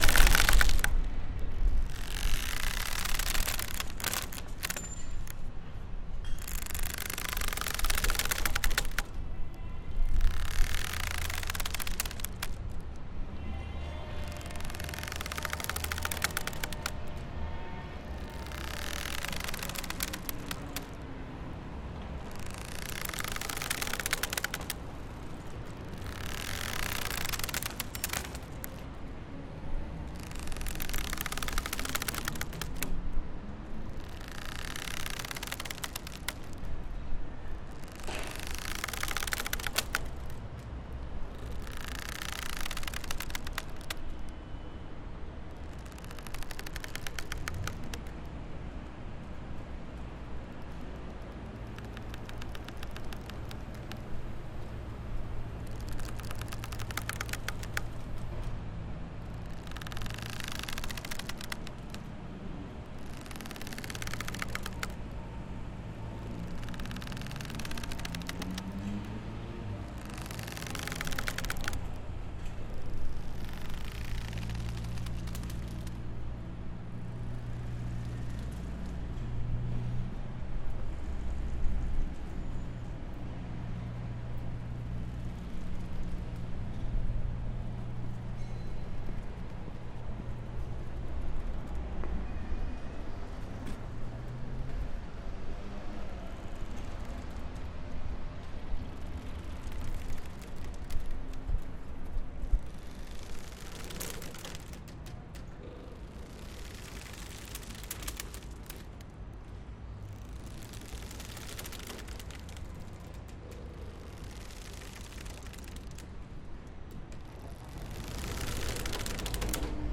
Field Recording-Port of Nice, France, Wednesday afternoon (audio 54)
port-of-nice.mp3